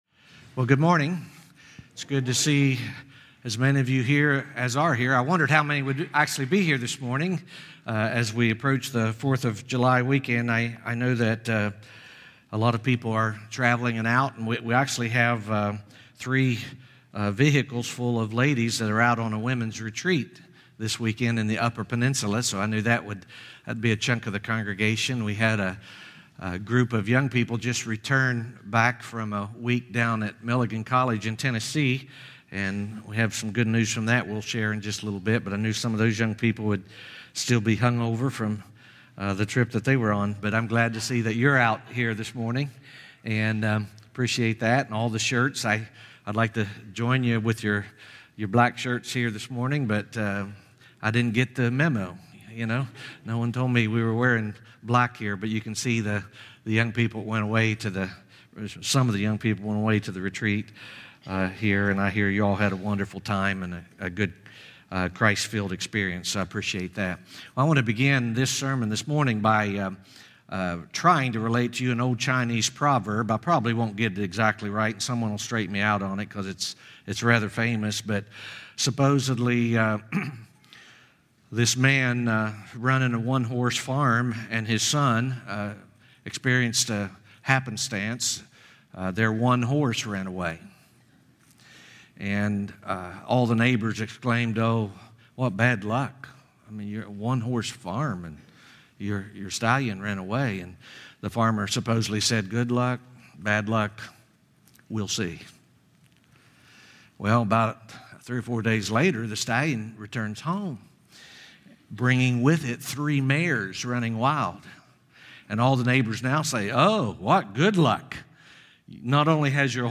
" Sermon Notes Facebook Tweet Link Share Link Send Email